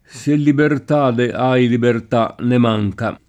libertà [libert#+] s. f. — rimaste a lungo nella tradiz. poet. le varianti ant. libertate [libert#te] e più ancóra libertade [libert#de]: Se libertade, ahi libertà!, ne manca [
S% llibert#de, #i libert#!, ne m#jka] (Carducci)